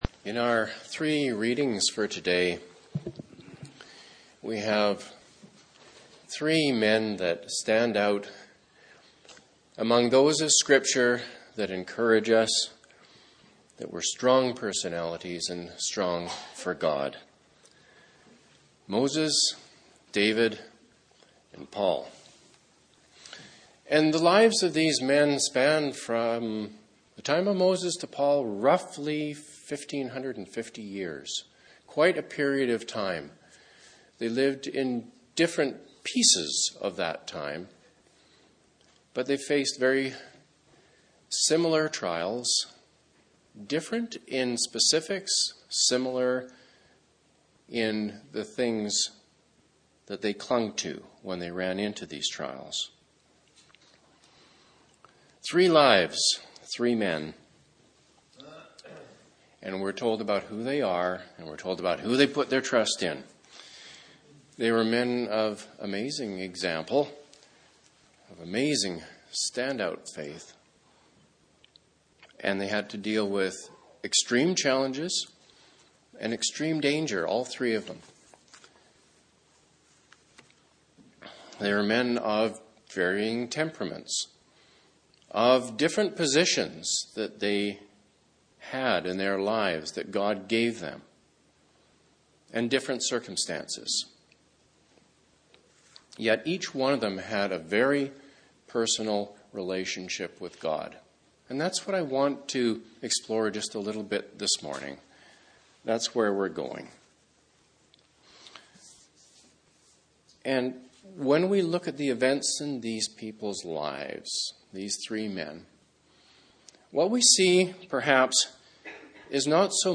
Exhortations